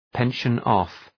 Προφορά
pension-off.mp3